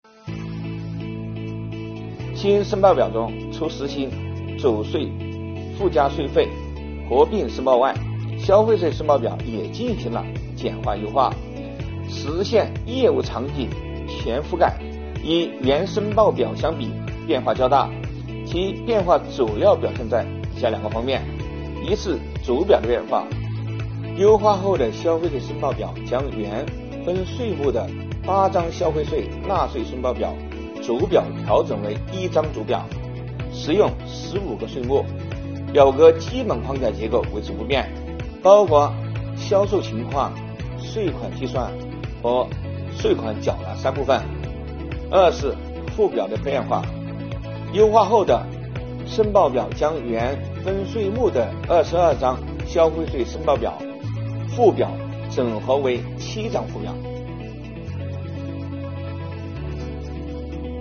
近日，国家税务总局推出最新一期“税务讲堂”课程，国家税务总局货物和劳务税司副司长张卫介绍增值税、消费税分别与附加税费申报表整合的相关内容。